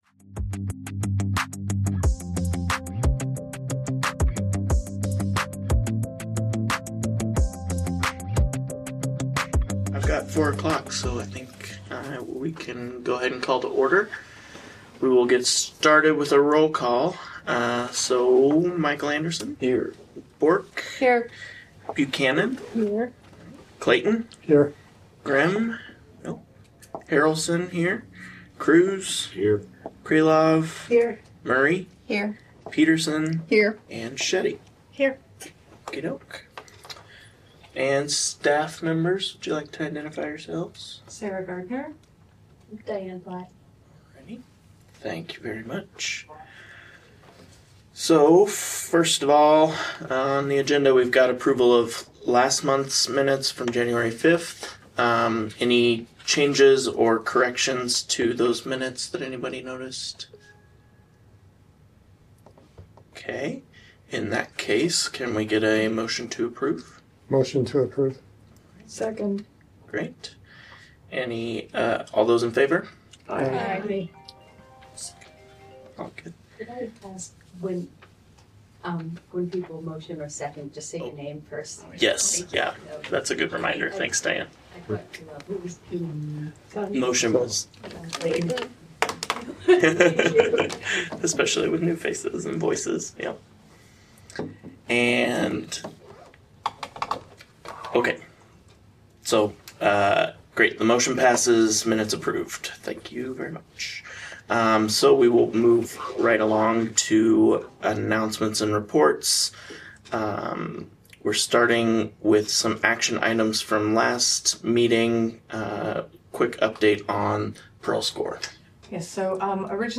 Regular monthly meeting of the Iowa City Climate Action Commission.